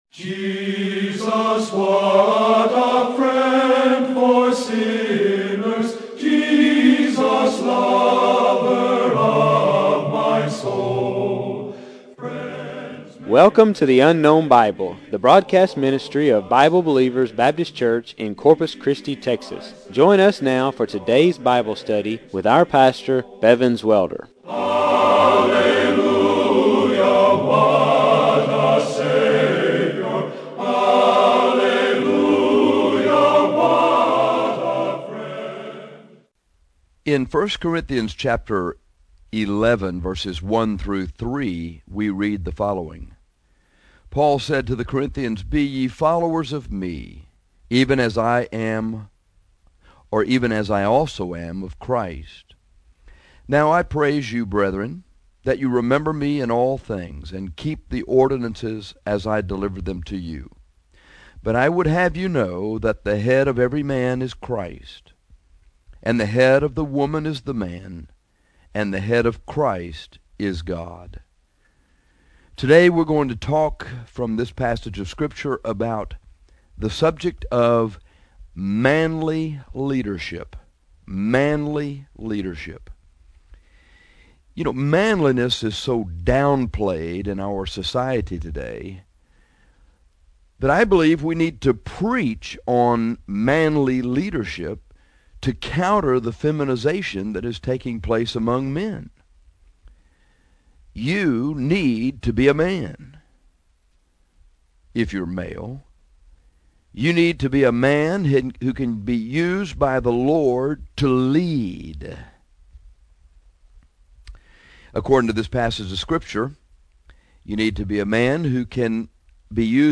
Manliness is so downplayed in our society today that we need to preach on manly leadership today to counter the feminization that is taking place among men.